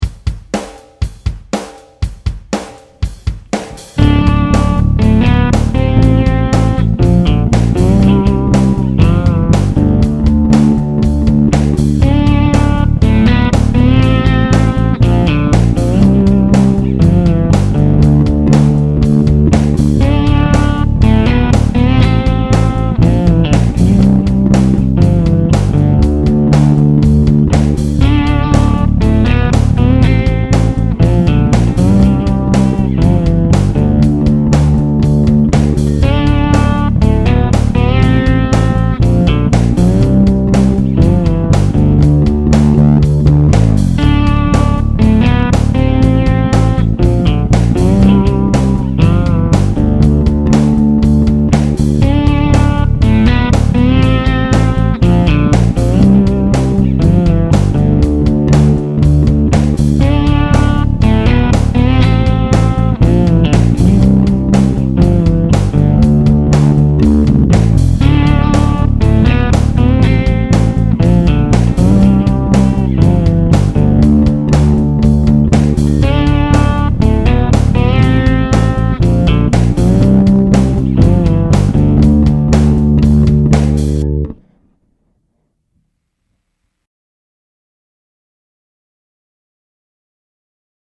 - I get by with a little help from a Garage Band Drum Loop
I mixed the bass line into the original post
GB_NDK06_bass.mp3